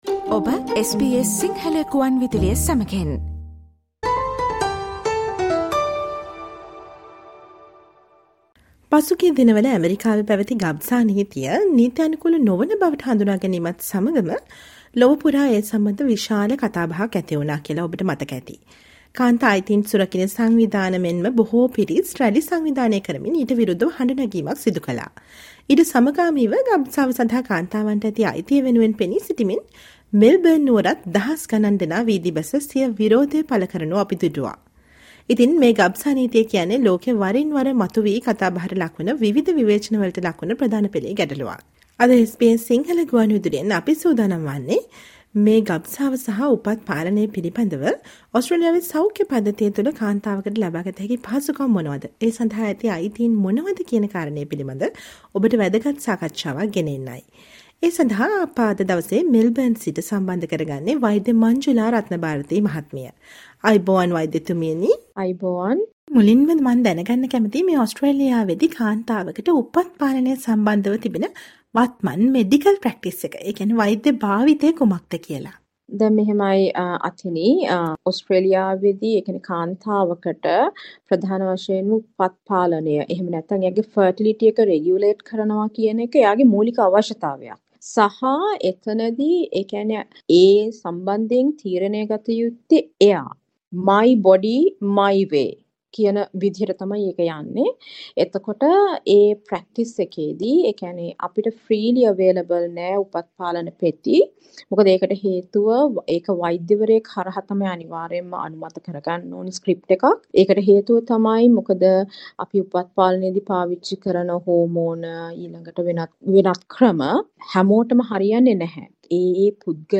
SBS සිංහල ගුවන් විදුලි සාකච්ඡාව